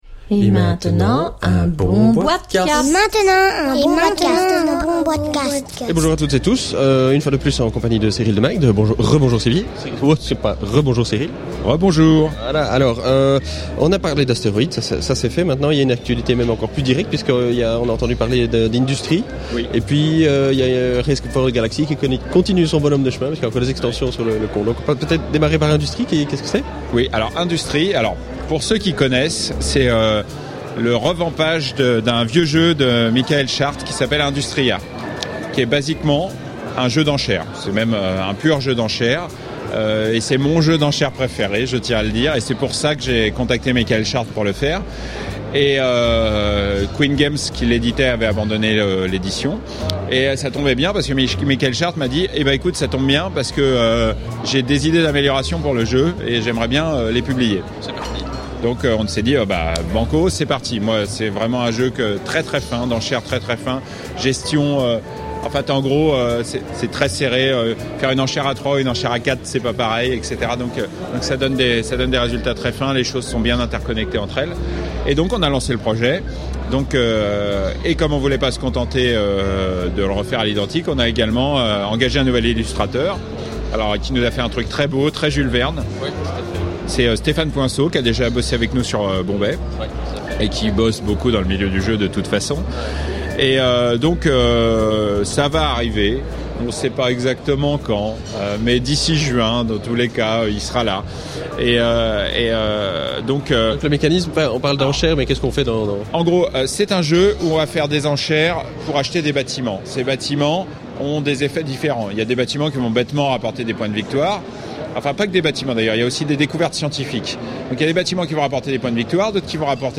(enregistré lors du salon international de la Nuremberg Toy Fair 2010)